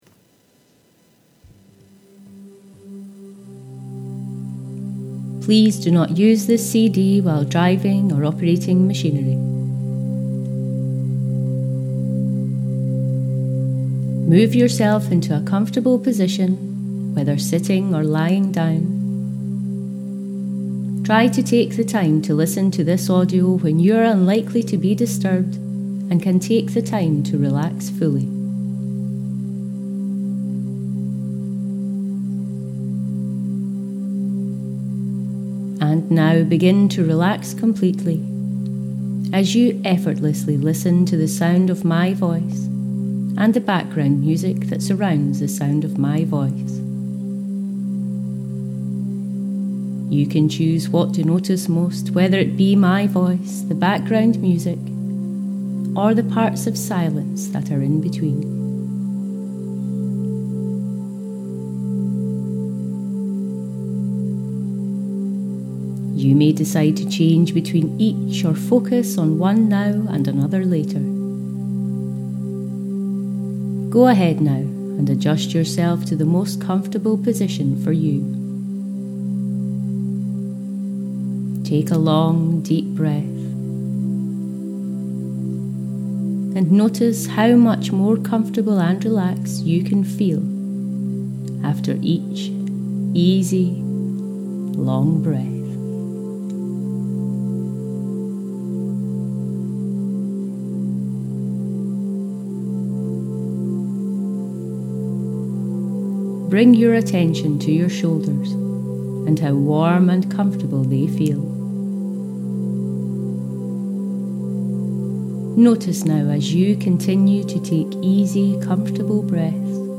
UFW-Hypnosis.mp3